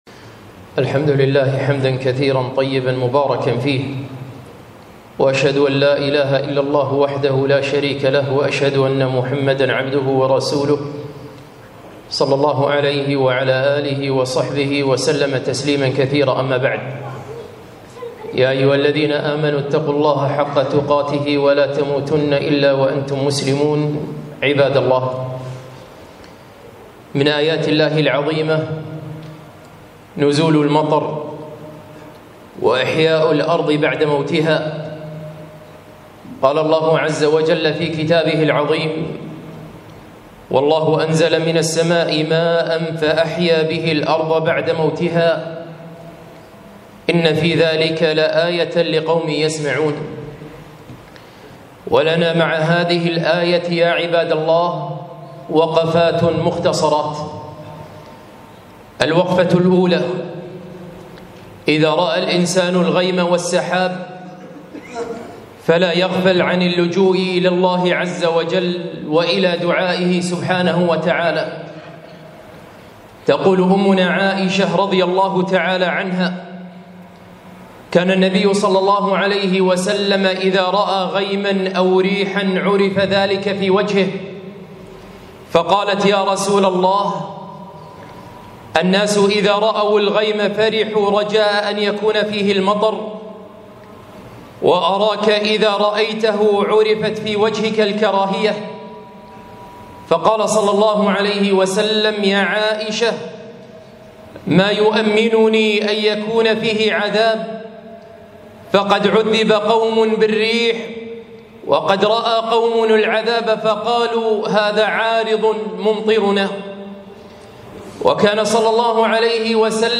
خطبة - وقفات من موسم الأمطار